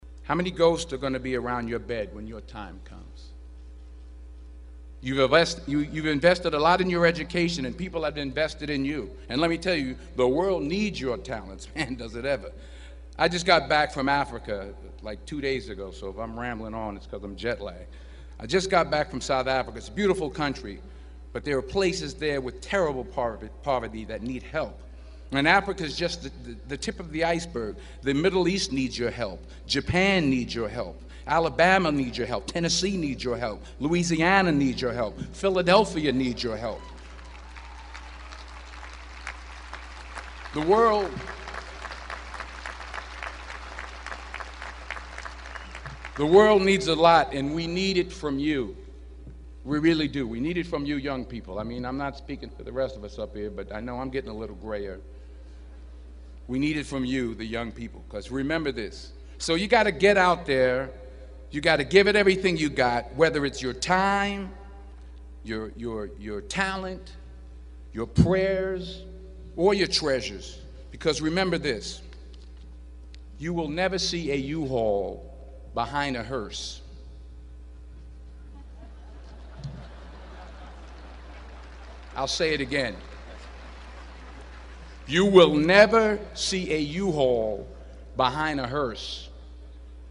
公众人物毕业演讲第427期:丹泽尔2011宾夕法尼亚大学(11) 听力文件下载—在线英语听力室